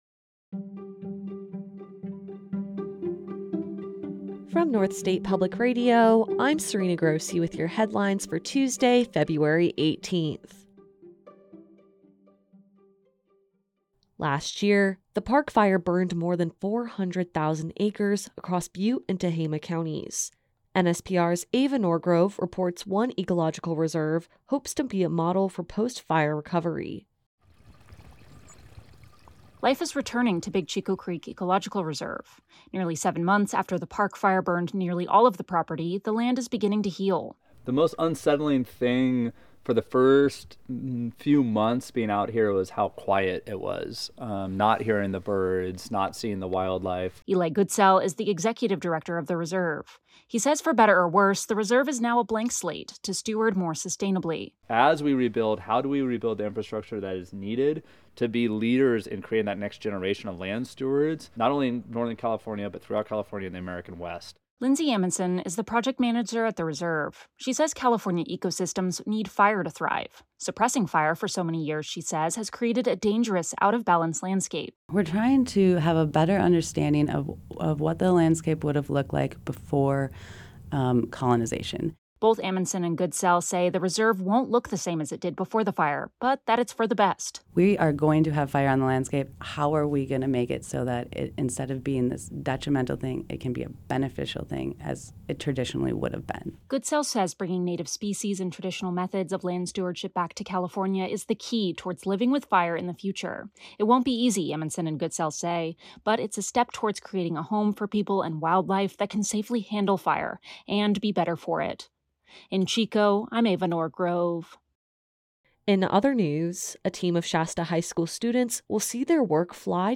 A daily podcast from NSPR featuring the news of the day from the North State and California in less than 10 minutes. Hosted by NSPR Staff, and available at 8:30 a.m. every weekday.